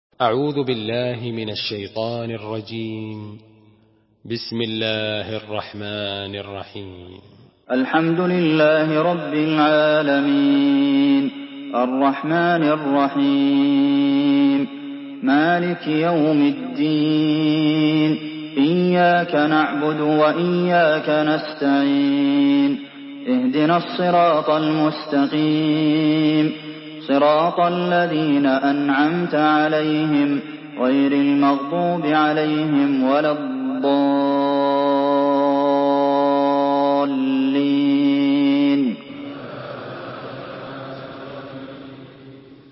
Surah الفاتحه MP3 in the Voice of عبد المحسن القاسم in حفص Narration
Listen and download the full recitation in MP3 format via direct and fast links in multiple qualities to your mobile phone.
مرتل